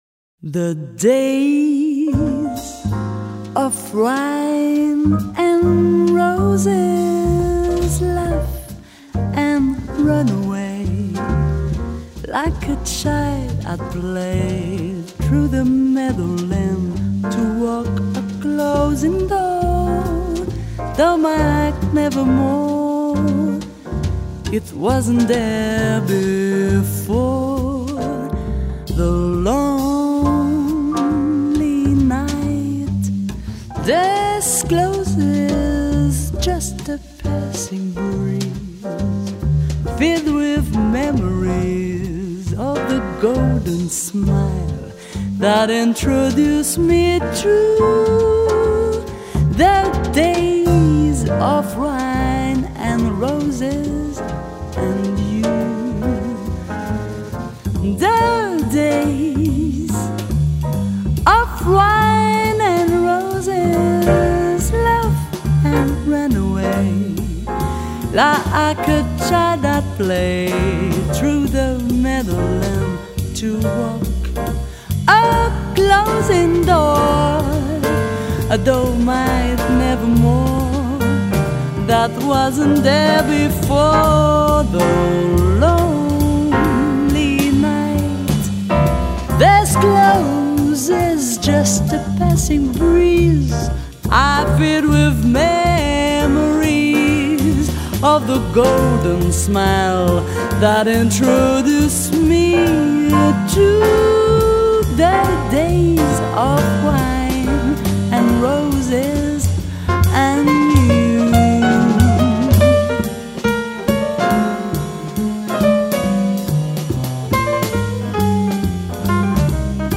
saxophone
piano
contrebasse
batterie